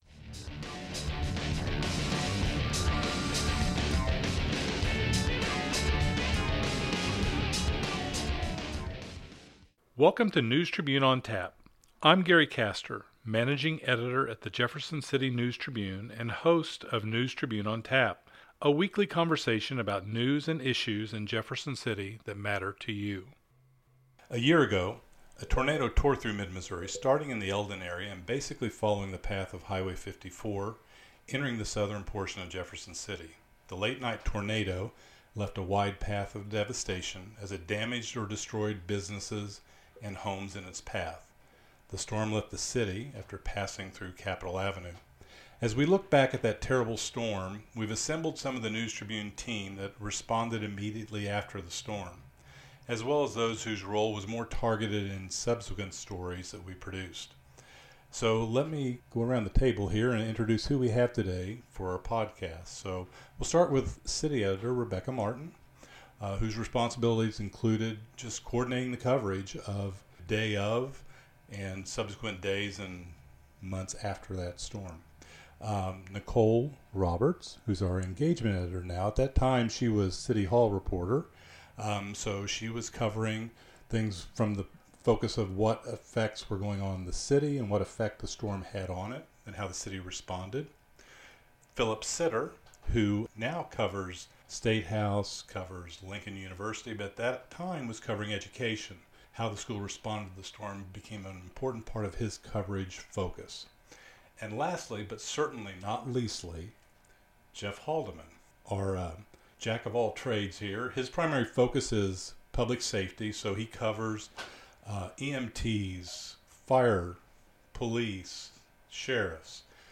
On May 22, 2019, an EF-3 tornado swept through Jefferson City and surrounding communities, leaving devastation behind. Now, one-year later, four News Tribune reporters recall the night of the tornado and what it was like in the days and weeks covering the natural disaster.